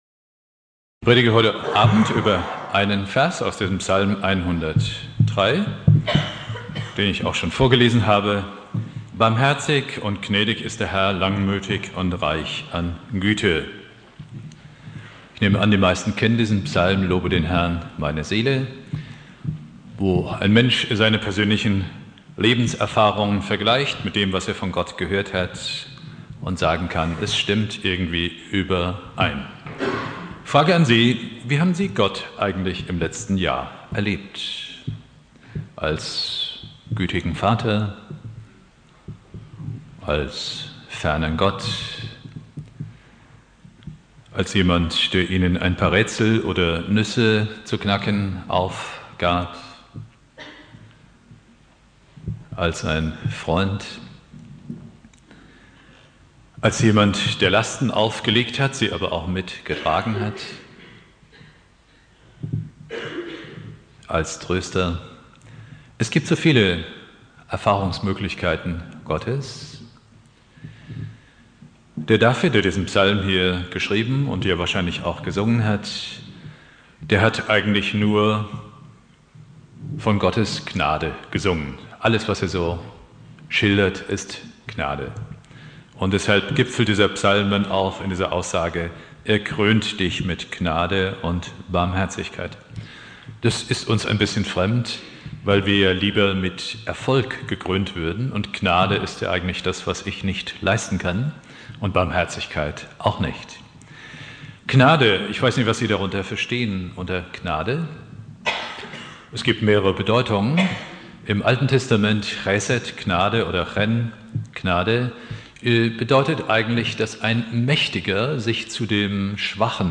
Predigt
Silvester